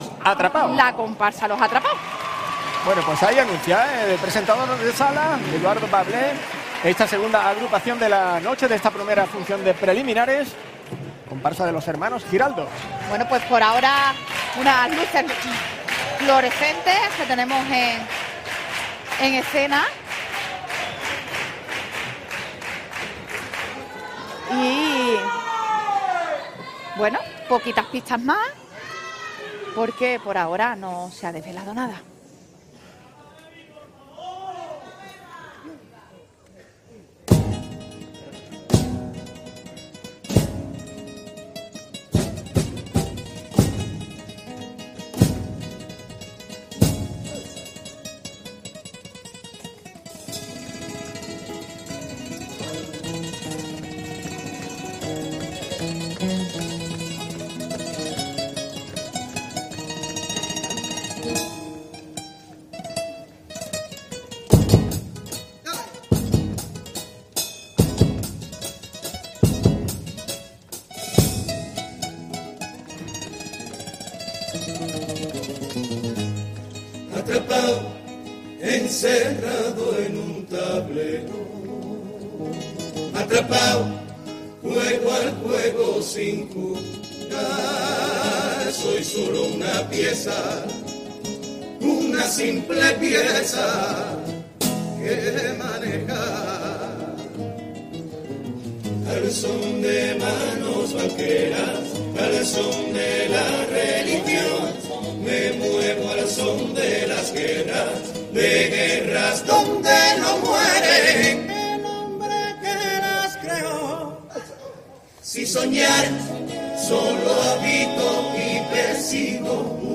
Concurso Oficial de Agrupaciones del Carnaval de Cádiz